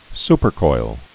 (spər-koil)